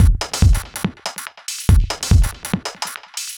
Index of /musicradar/uk-garage-samples/142bpm Lines n Loops/Beats
GA_BeatAFilter142-08.wav